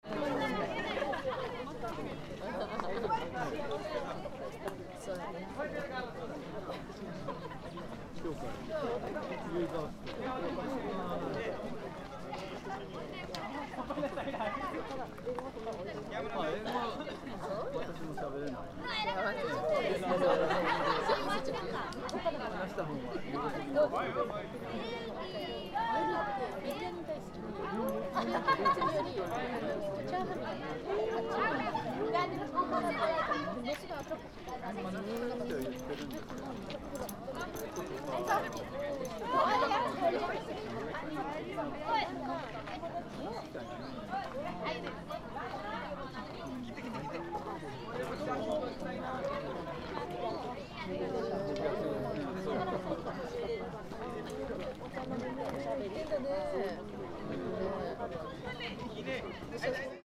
Mountaintop of Mt. Hanami
Because of the excursion of a Japanese Language School, so many people from foreign countries were there.
The voices of many wild birds, like Japanese nightingales and
pheasants, were heard as usual year.